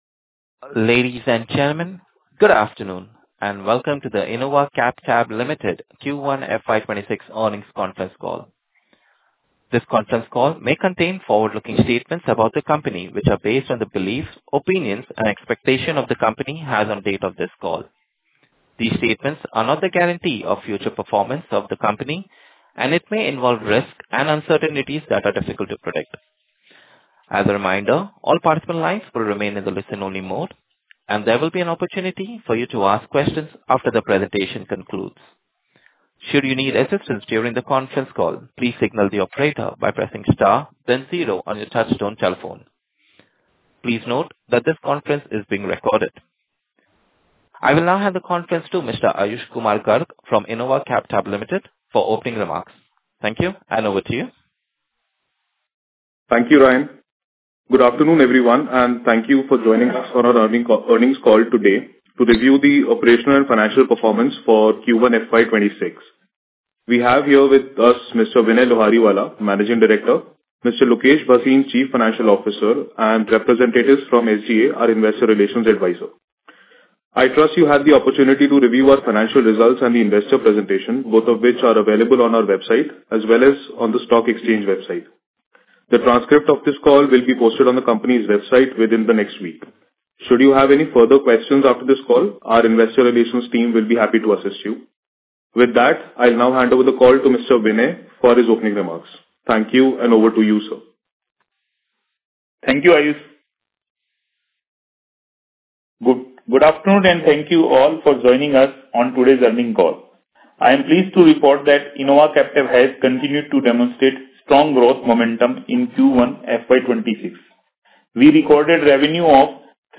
Recording of Earning Call